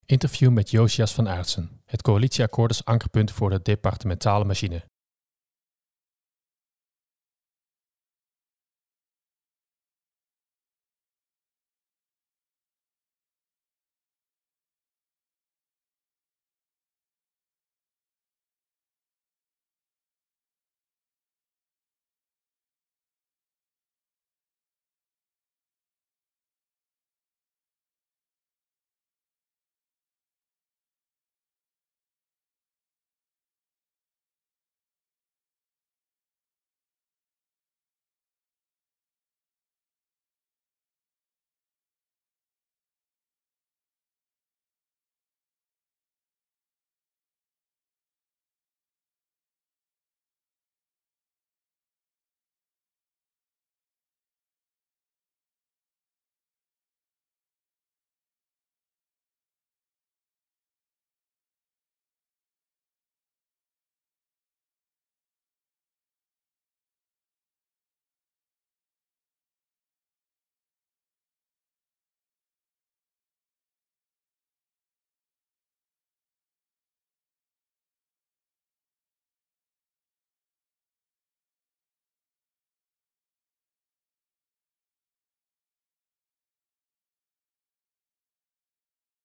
Interview met Jozias van Aartsen